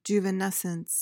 PRONUNCIATION:
(joo-vuh-NES-uhns)